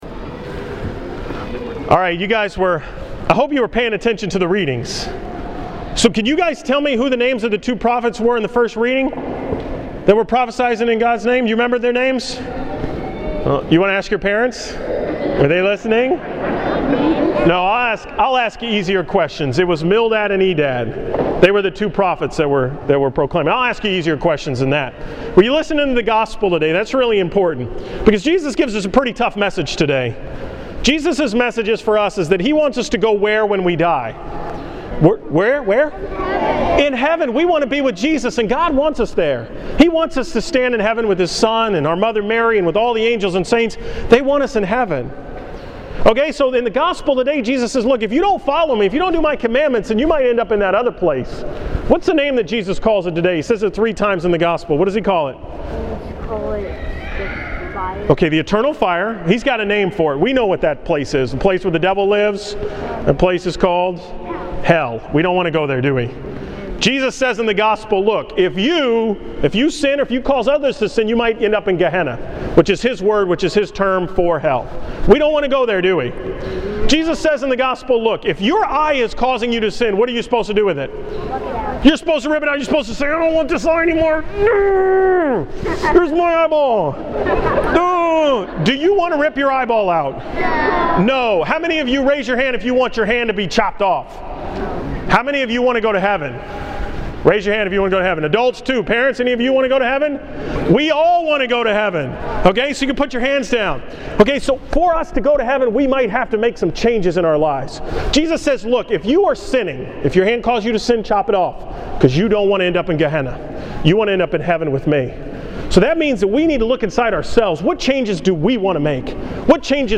Homily from Sunday, September 30th 26th Sunday in Ordinary Time